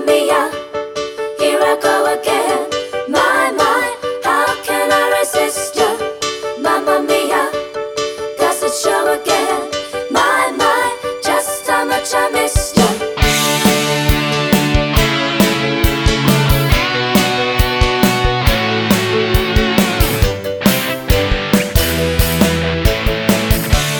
No Bass Pop (1970s) 3:30 Buy £1.50